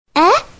人物音效
哎（疑惑）